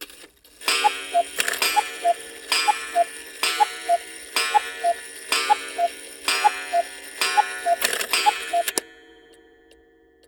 cuckoo-clock-09.wav